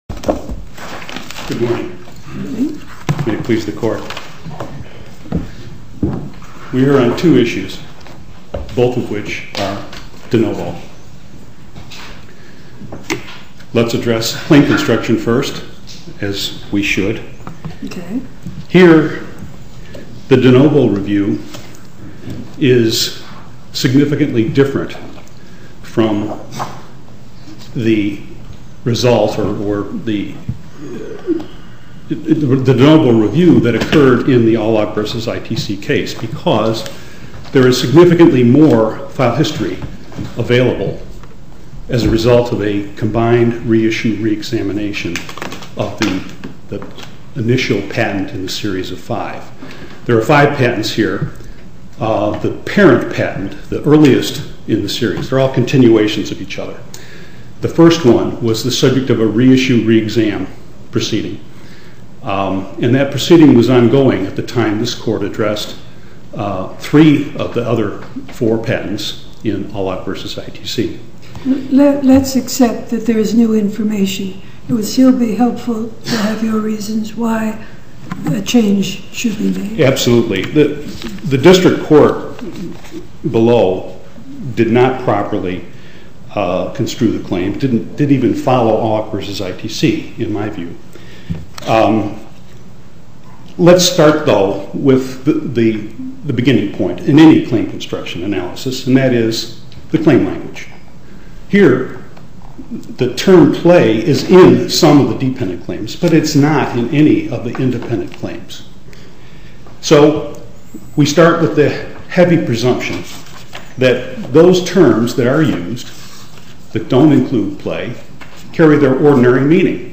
Oral argument audio posted: ALLOC V. PERGO (mp3) Appeal Number: 2011-1094 To listen to more oral argument recordings, follow this link: Listen To Oral Arguments.